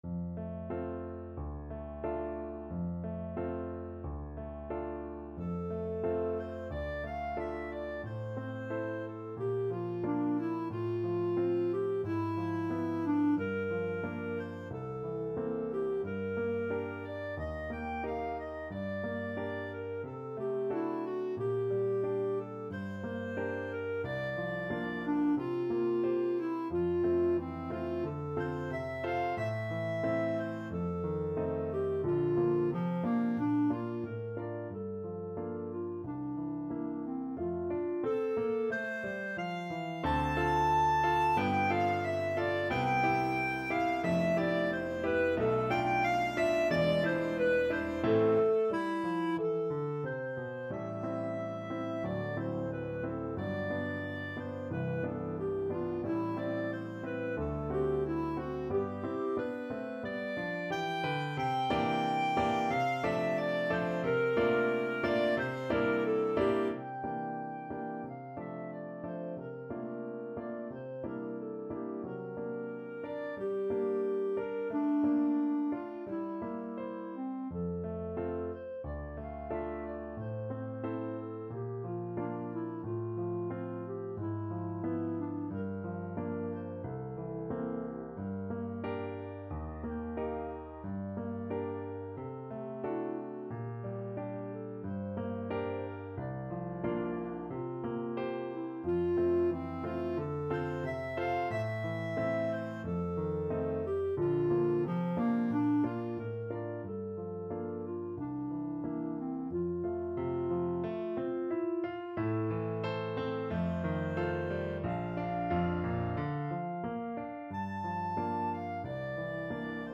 Classical
2/4 (View more 2/4 Music)
~ = 100 Allegretto con moto =90